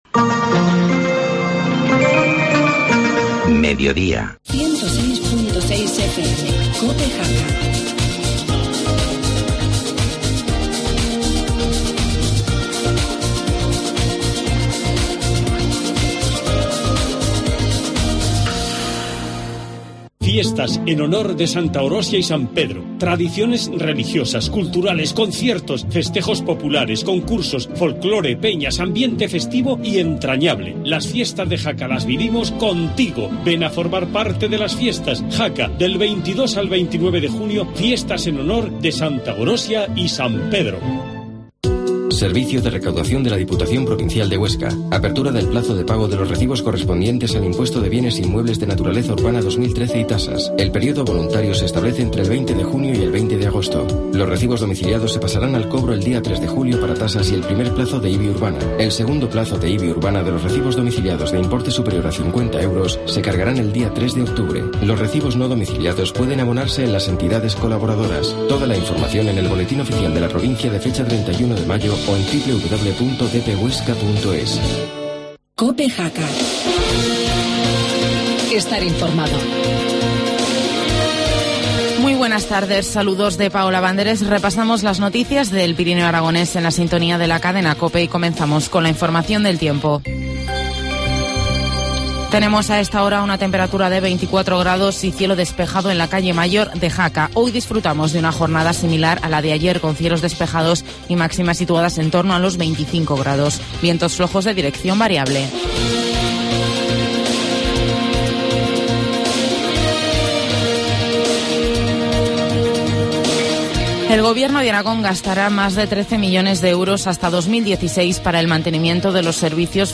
Informativo mediodía, miércoles 26 de junio